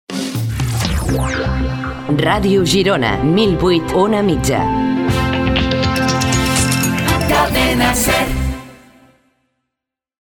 Identificació i freqüència